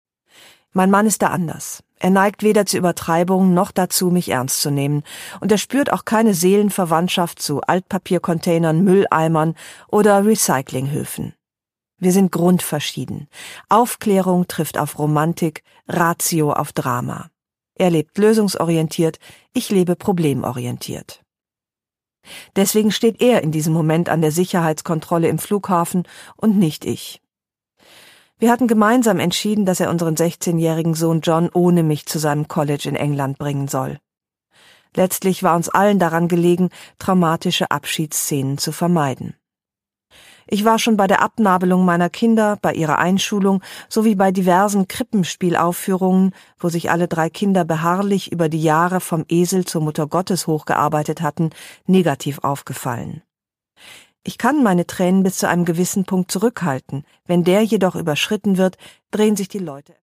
Produkttyp: Hörbuch-Download
Gelesen von: Ildikó von Kürthy